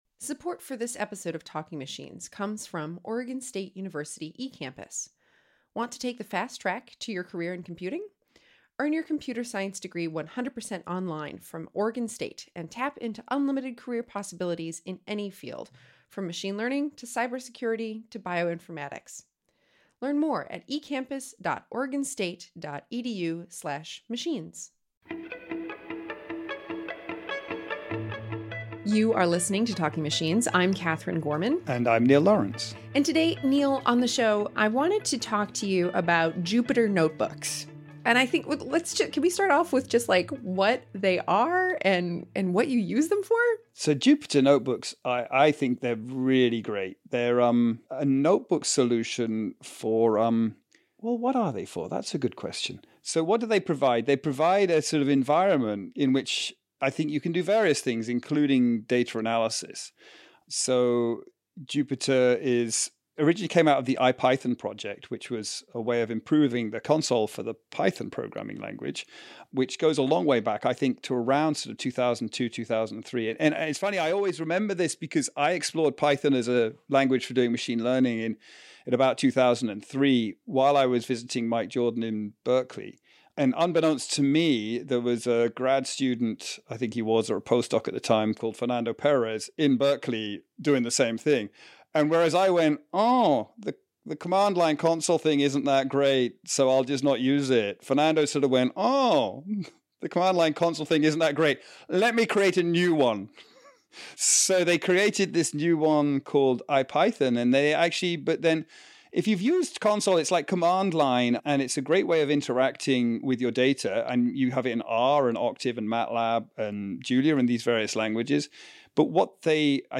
we hear an interview